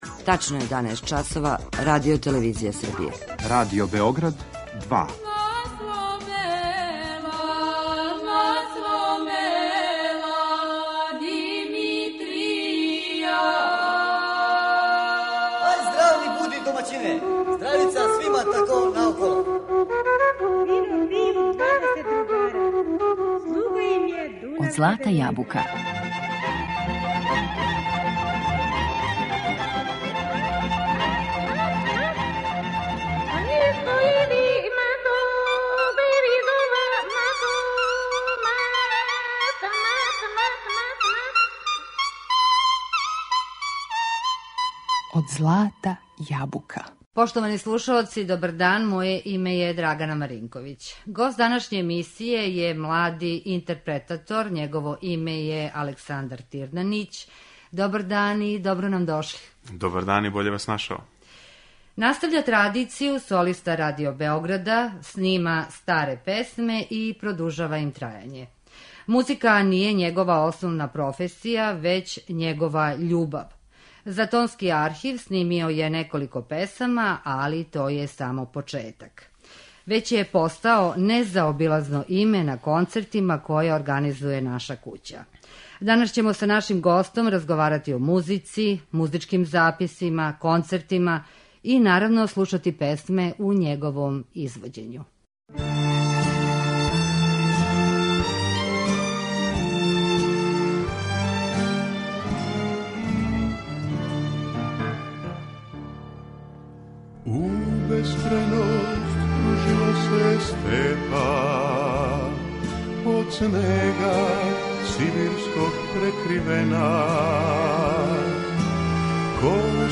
Са нашим гостом разговараћемо о музици, записима, концертима и слушаћемо песме у његовом извођењу.